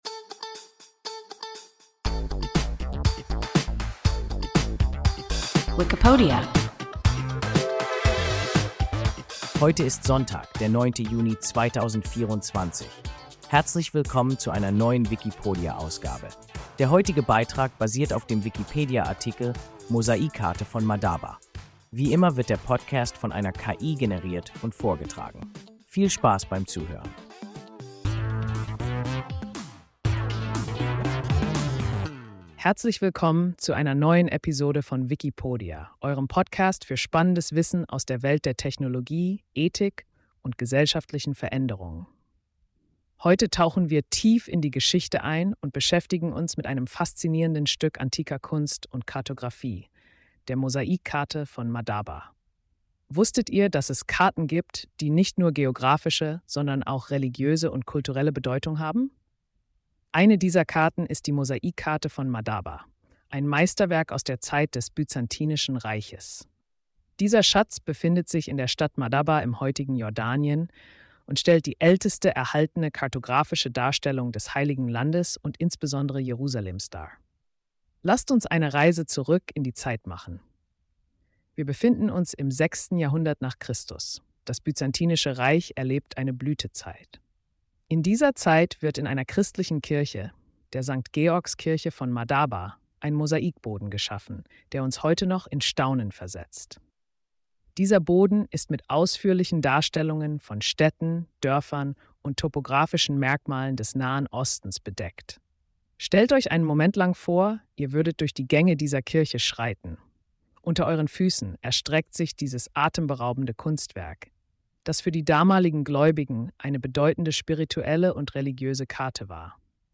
Mosaikkarte von Madaba – WIKIPODIA – ein KI Podcast